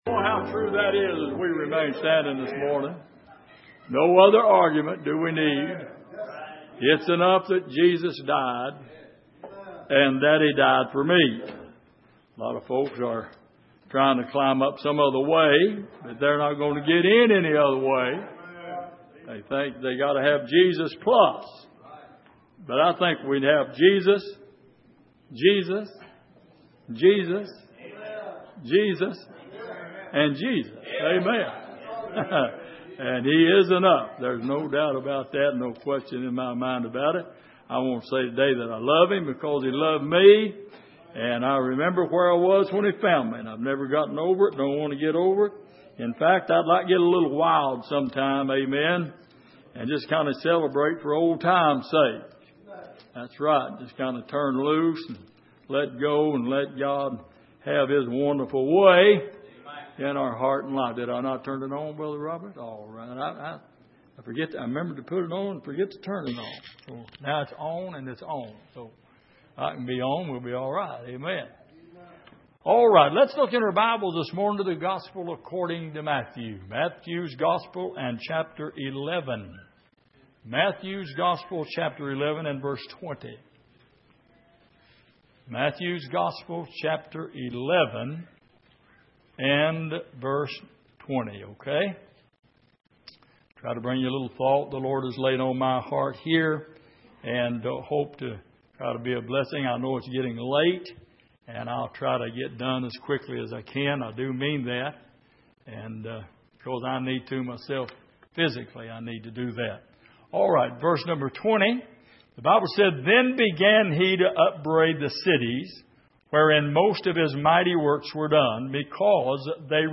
Matthew 11:20-30 Service: Sunday Morning Learning About Jesus « Are You Born Again?